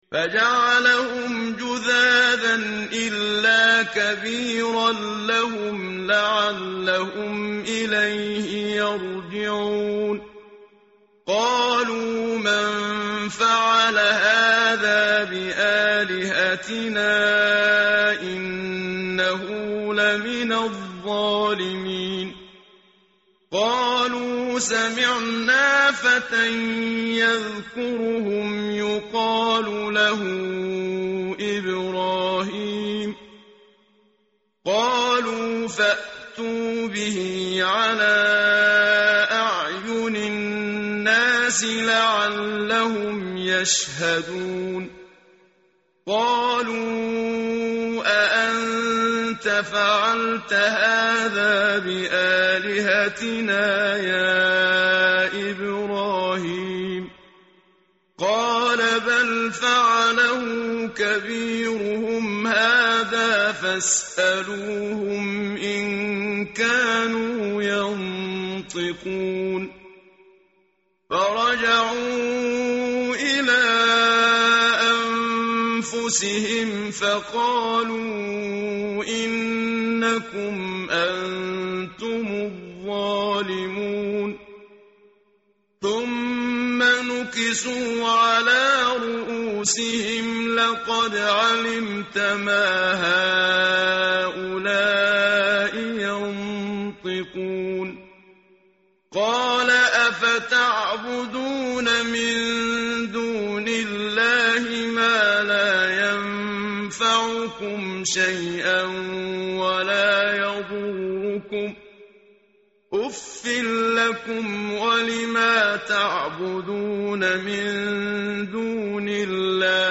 tartil_menshavi_page_327.mp3